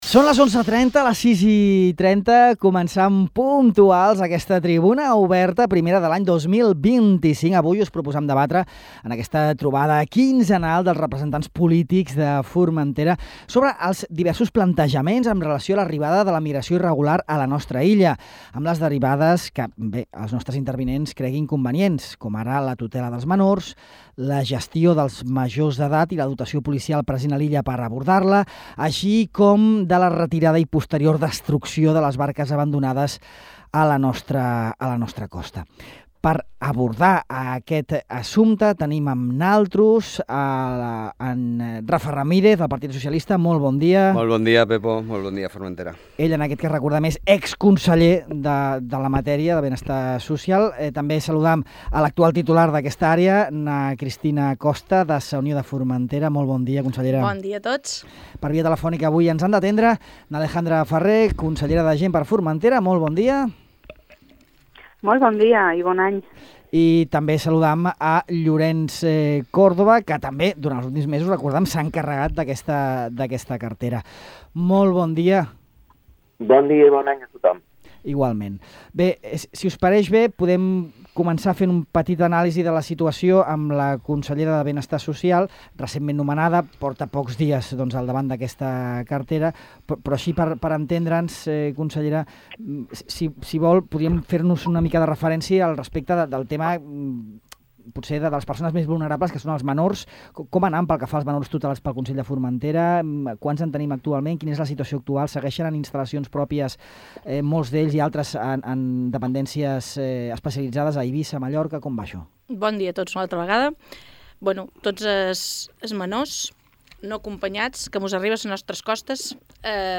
El fenomen migratori: tutela de menors i barques abandonades, a la tertúlia política
Cristina Costa (Sa Unió), Alejandra Ferrer (GxF), Rafa Ramírez (PSOE) i Llorenç Córdoba participen en la primera Tribuna Oberta de l’any a Ràdio Illa, dedicada a debatre sobre els efectes del fenomen de les migracions irregulars a Formentera. En aquesta nova edició de la tertúlia política quinzenal, els representants del ple aborden els seus punts de vista sobre la tutela del centenar de menors no acompanyats que exerceix el Consell de Formentera i sobre la situació de les aproximadament deu barques de la migració abandonades en el litoral de l’illa.